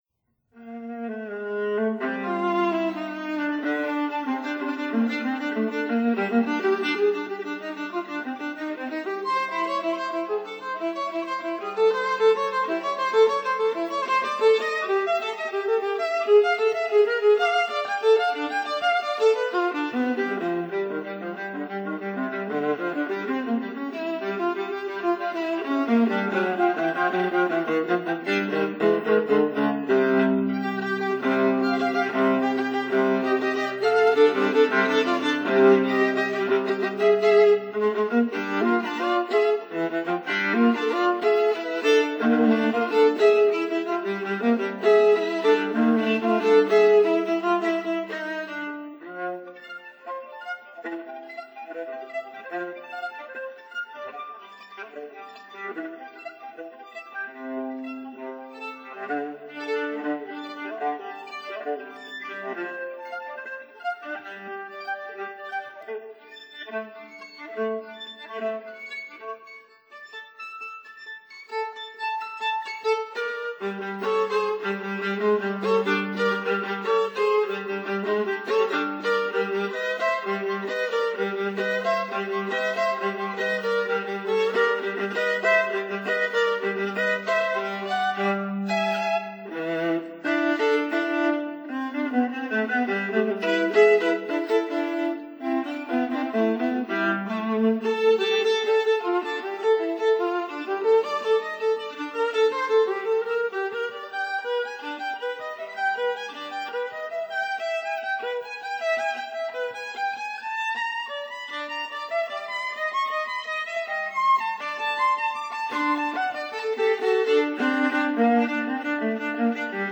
violin
viola
cello